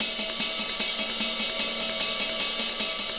Ride 01.wav